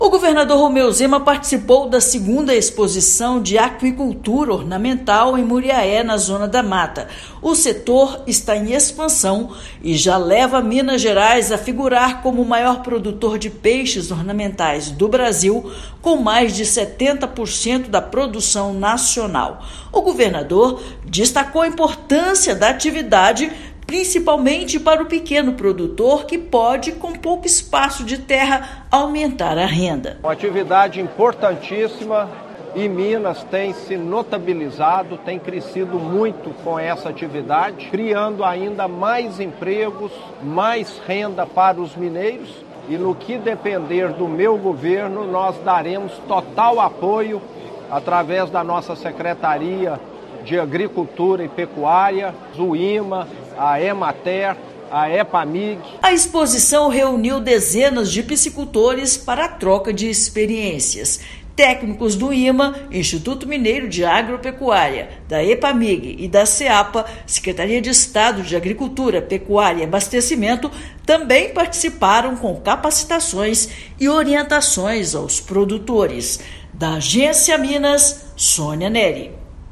Com mais de 400 produtores, estado responde por 70% da produção da aquicultura ornamental do país. Ouça matéria de rádio.